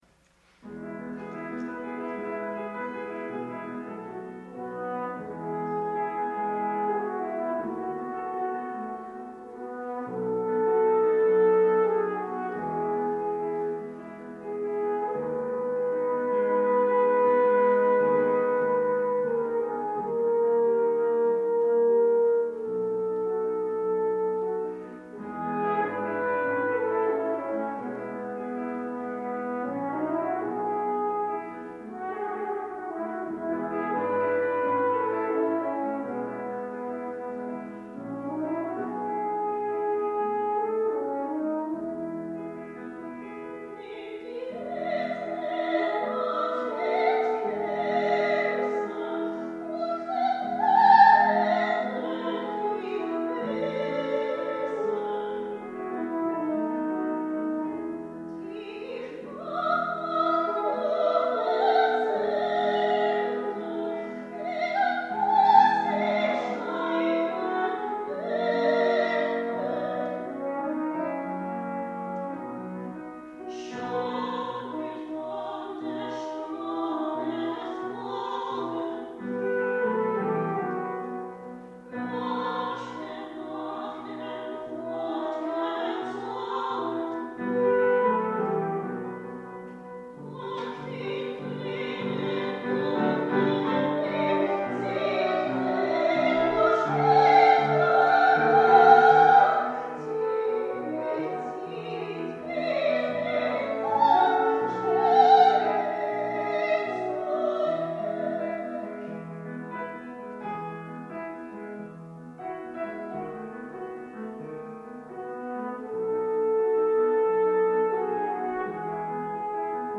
Art song